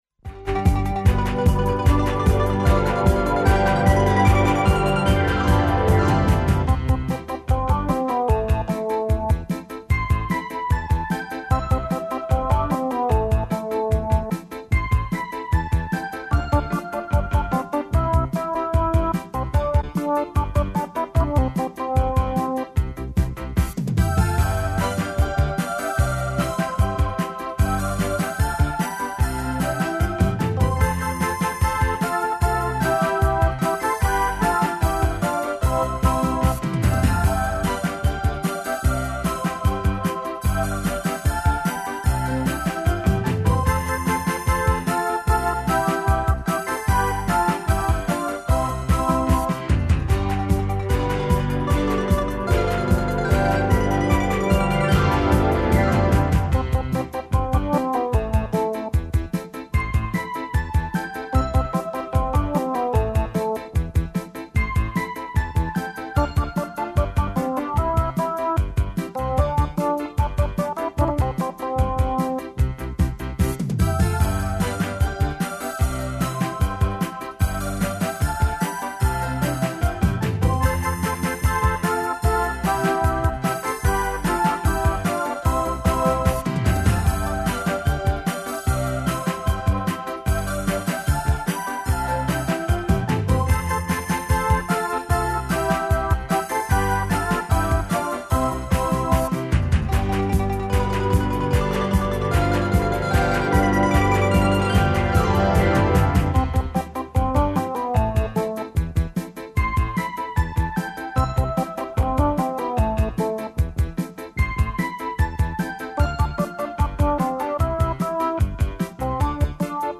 мајстор за маштање и лепе речи, и деца